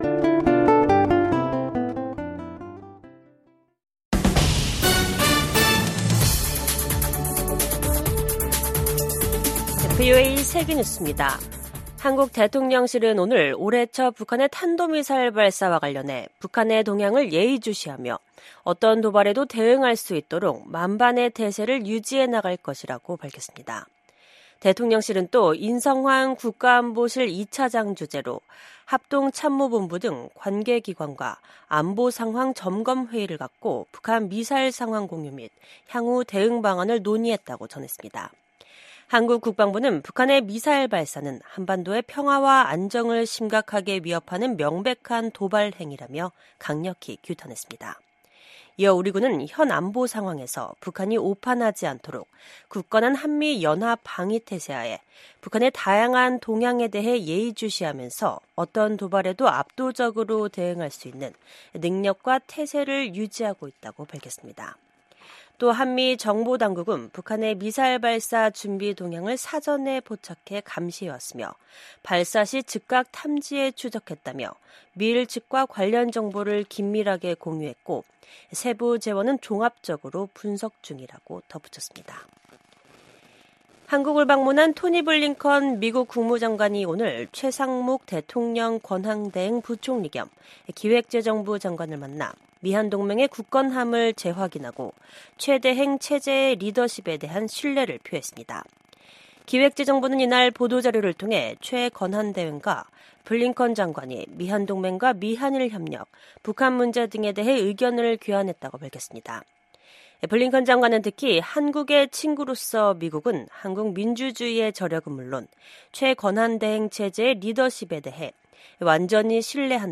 VOA 한국어 간판 뉴스 프로그램 '뉴스 투데이', 2025년 1월 6일 2부 방송입니다. 한국의 비상계엄 사태 이후 토니 블링컨 미국 국무장관이 한국을 처음으로 방문했습니다. 한국을 방문한 블링컨 장관과 조태열 외교장관이 서울 외교부 청사에서 미한 외교장관 회담을 연 오늘 북한은 극초음속 미사일로 추정되는 탄도미사일을 동해상에 발사했습니다.